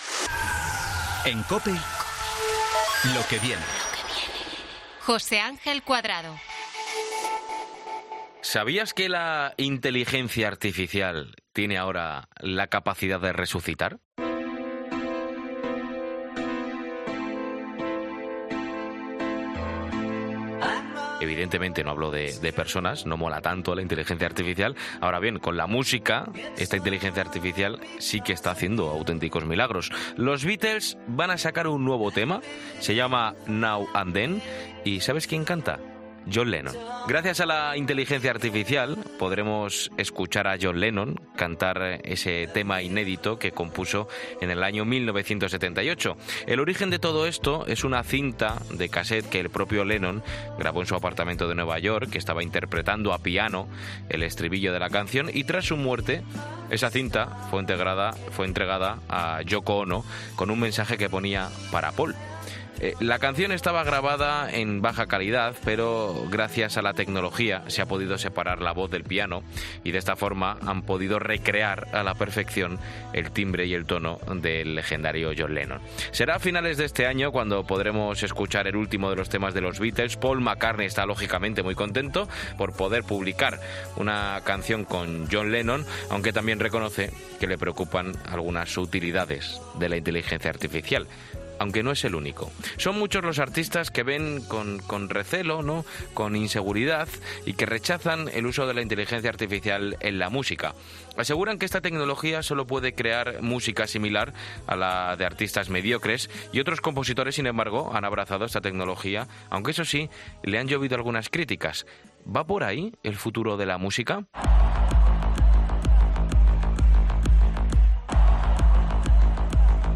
Javier Sierra, colabora de 'Herrera en COPE', analiza los peligros que puede acarrear la inteligencia artificial y nos descubre al hermano malvado de ChatGPT, ChaosGPT, que tiene como misión "destruir a la humanidad".